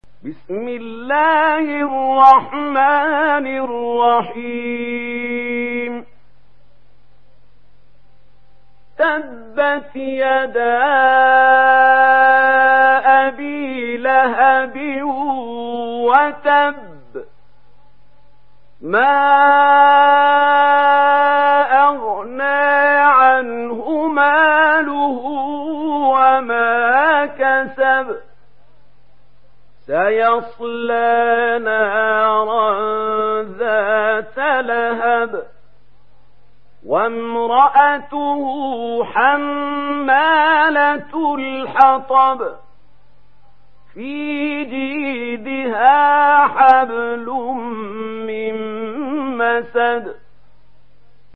Sourate Al Masad Télécharger mp3 Mahmoud Khalil Al Hussary Riwayat Warch an Nafi, Téléchargez le Coran et écoutez les liens directs complets mp3